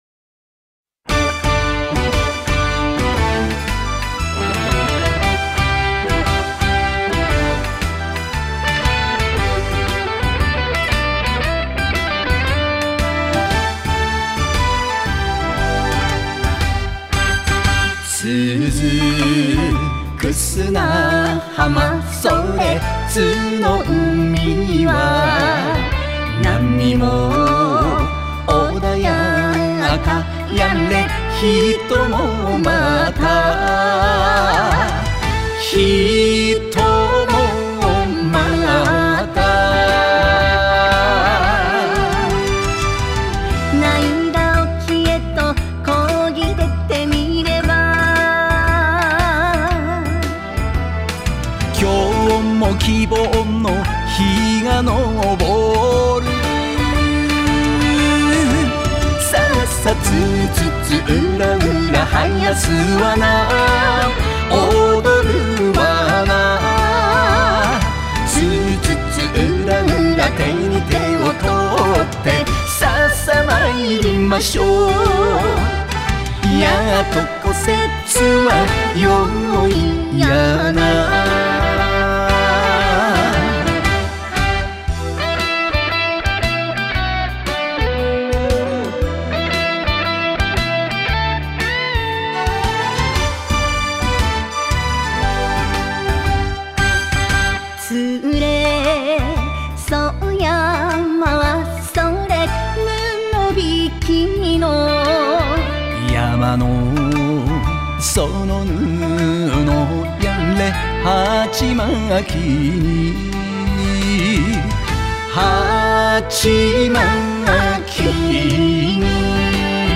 動画 津のまち音頭（動画正面から） （外部リンク） 津のまち音頭（動画背面から） （外部リンク） 音源ダウンロード 津のまち音頭（歌あり） （mp3 5.1MB） 津のまち音頭（カラオケ） （mp3 5.1MB） 「津のまち音頭 つぅ津々浦々」の動画DVDと音源CDを観光振興課、各総合支所で貸し出しますので、希望する人はお問い合わせください。